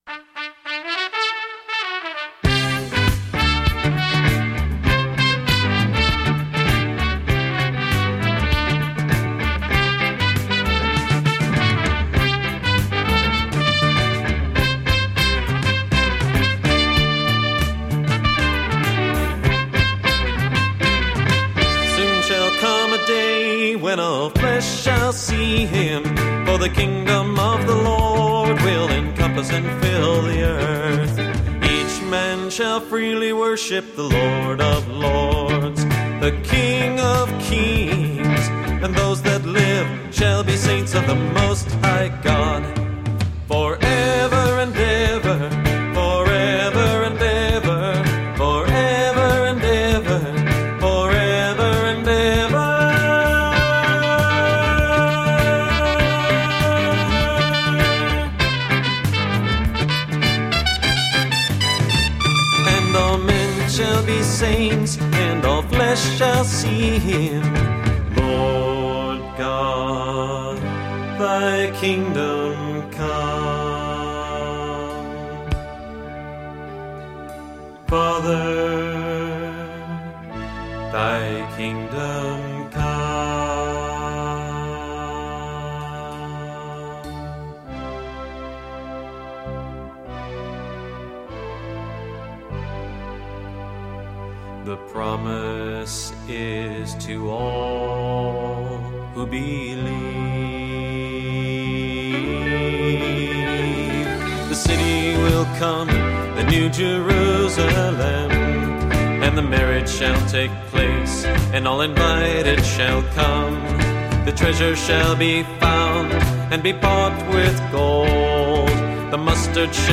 professionally recorded in 1982
bass guitar
percussion
electric and acoustic guitars
trumpet
English horn, saxophone and clarinet
background vocals